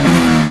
rr3-assets/files/.depot/audio/sfx/gearshifts/f1/renault_downshift_3.wav
renault_downshift_3.wav